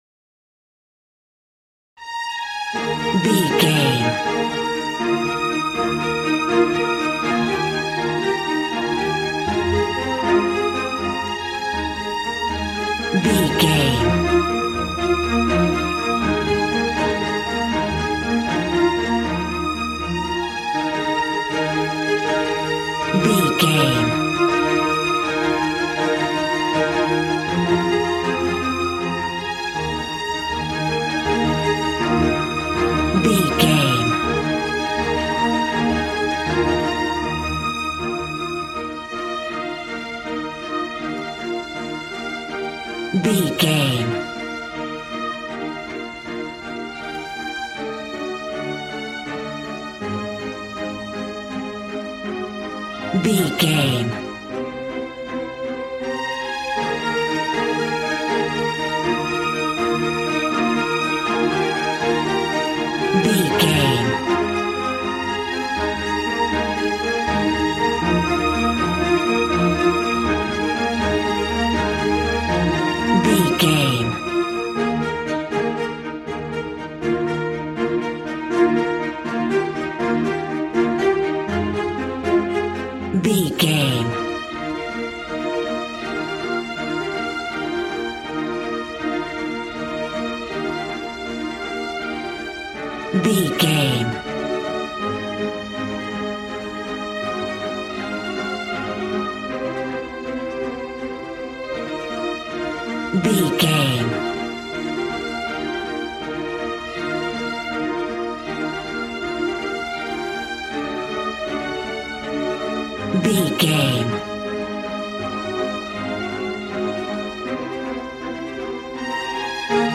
Ionian/Major
E♭
regal
strings
brass